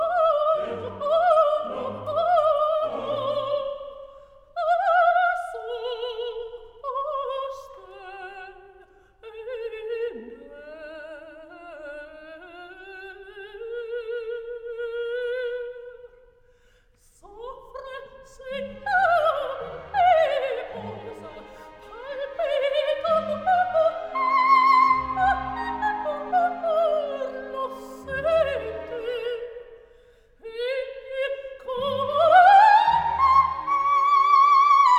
# Classical